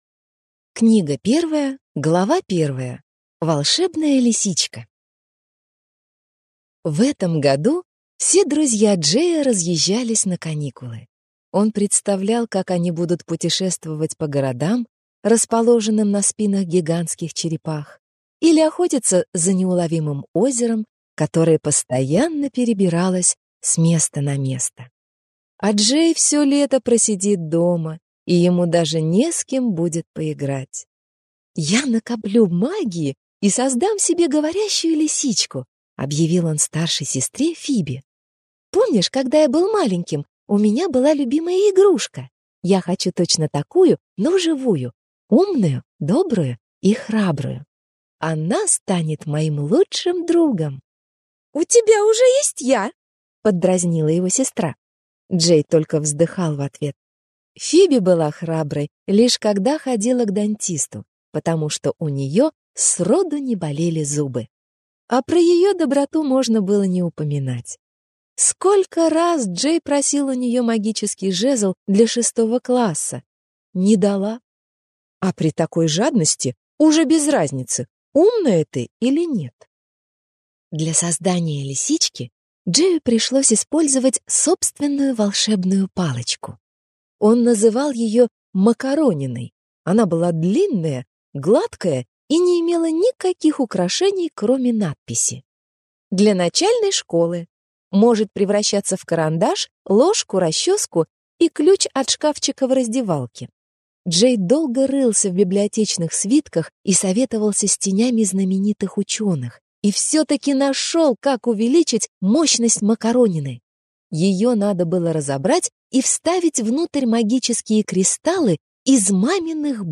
Аудиокнига Неоники и лисичка Наруке | Библиотека аудиокниг